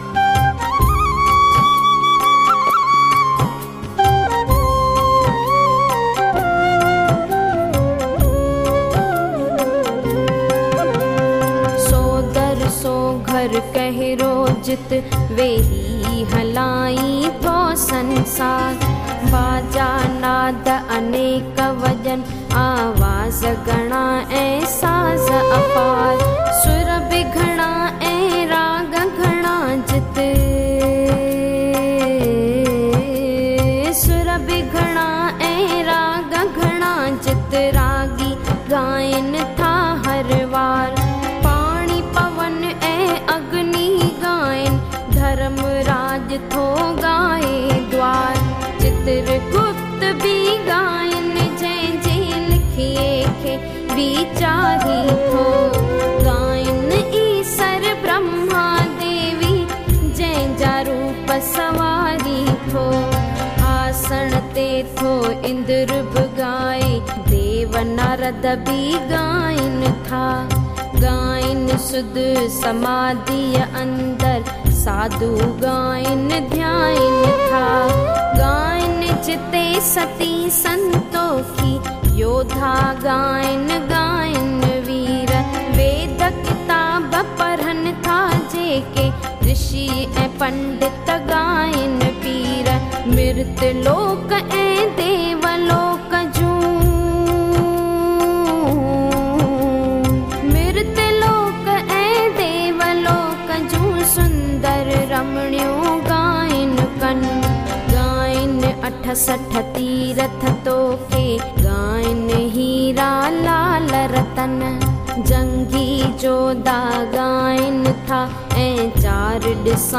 In Melodious Voice